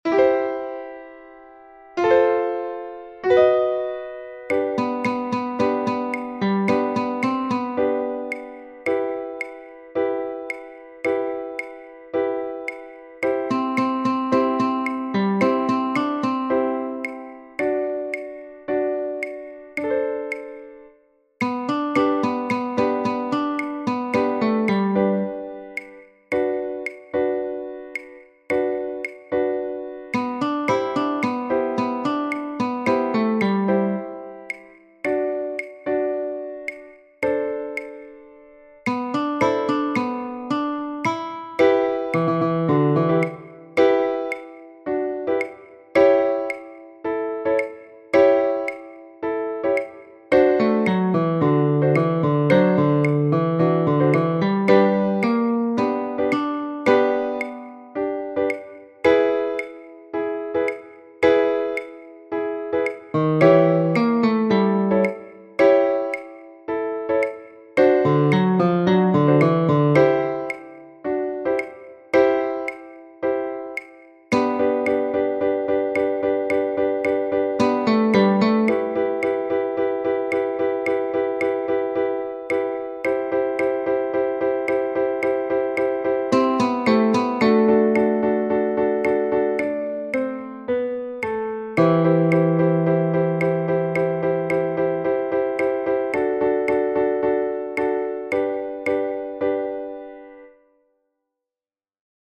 silent bars